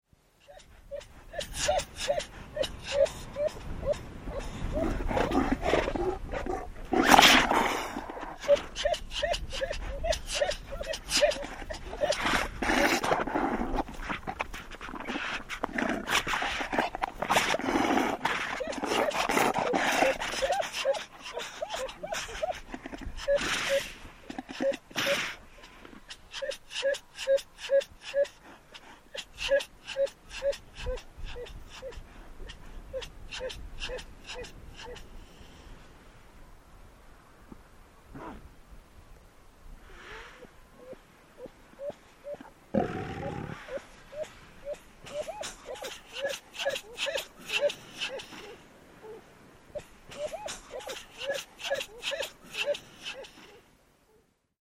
В коллекции — рычание, кряхтение и другие характерные голосовые реакции этих морских животных.
Два морских котика разбираются в отношениях